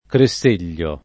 [ kre SS% l’l’o ]